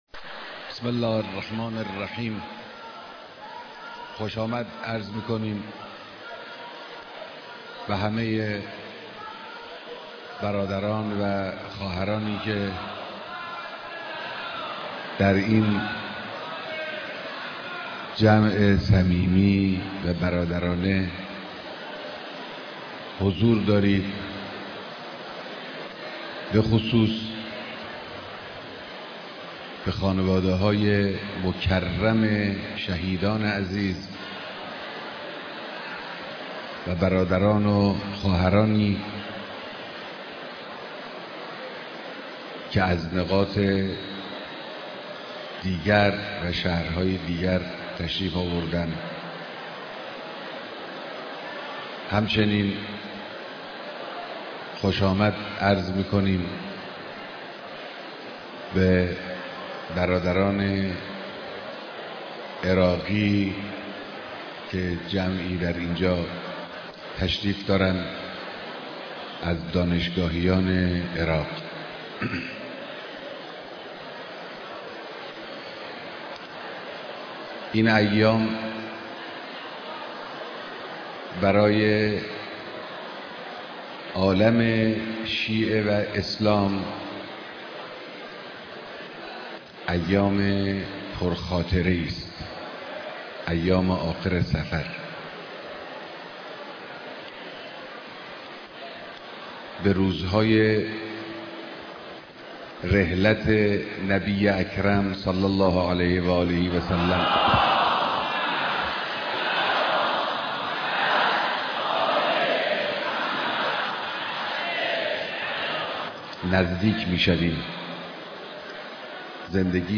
ديدار هزاران نفر از قشرهاى مختلف مردم در آستانه ايام رحلت نبى مكرم اسلام (ص)